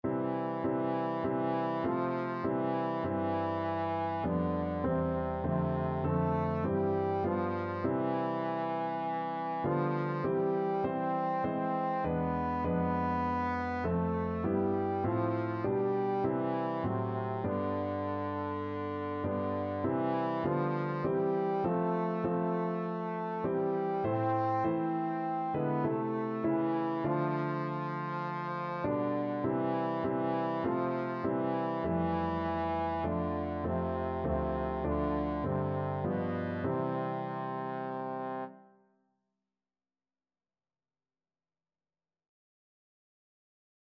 Trombone
C major (Sounding Pitch) (View more C major Music for Trombone )
4/4 (View more 4/4 Music)
B3-C5